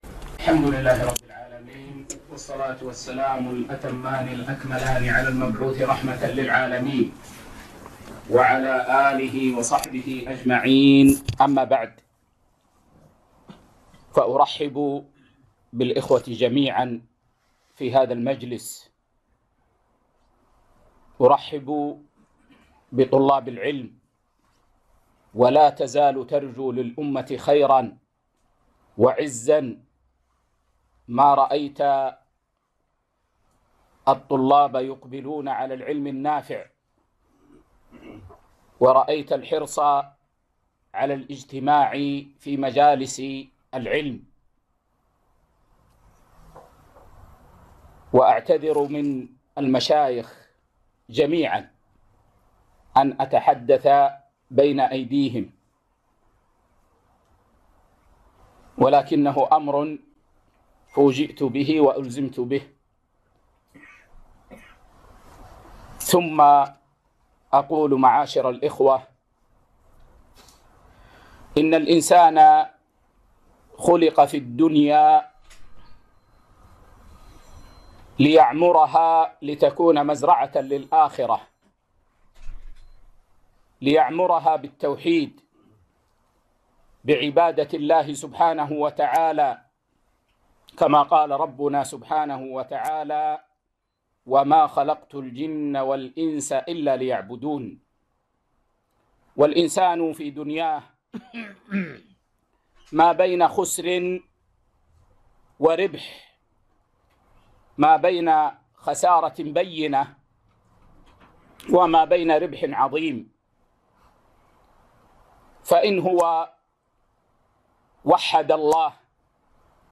كلمة - ( طريق المتقين ) في الديوانية ضمن دورة الخليفة الراشد 23